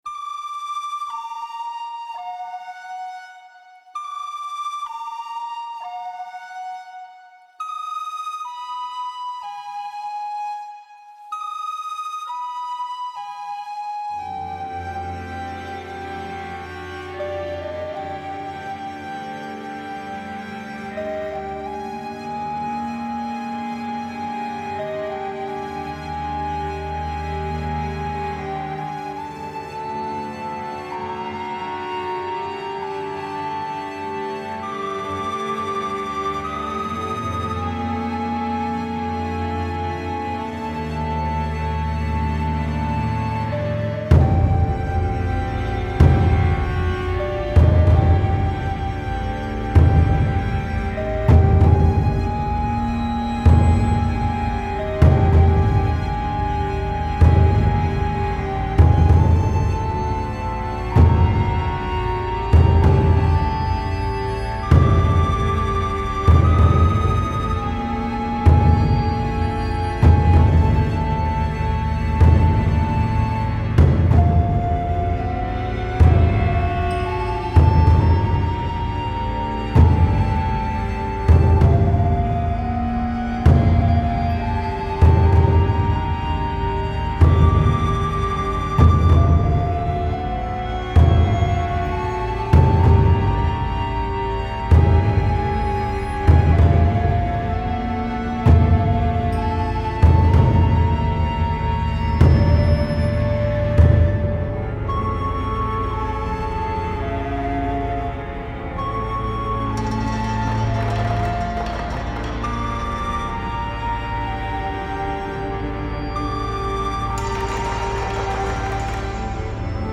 Style Style AmbientFolkSoundtrack
Mood Mood DarkMysteriousScarySuspenseful
Featured Featured CelloDrumsPercussionStringsWoodwind
BPM BPM 64